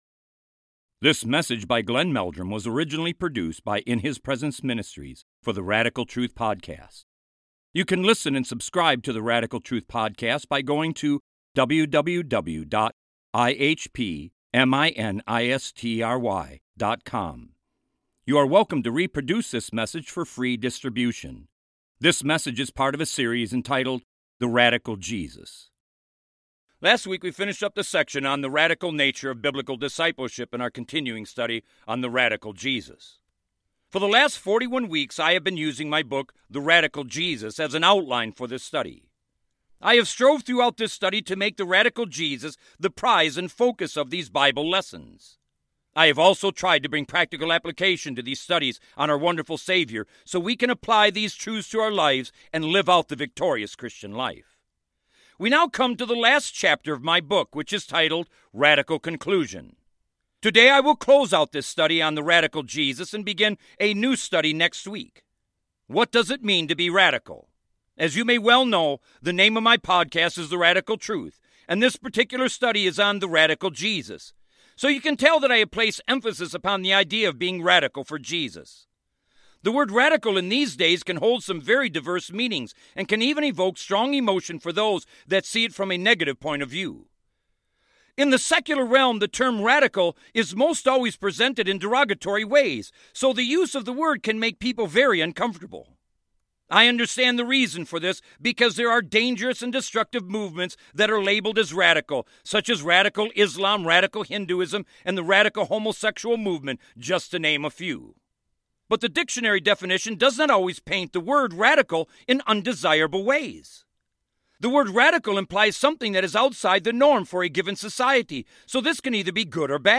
In this sermon, the speaker emphasizes the importance of having an all-consuming devotion to God. He uses the analogy of a long-distance runner striving to win a prize, highlighting the need for strong exertions and a singular focus on the goal.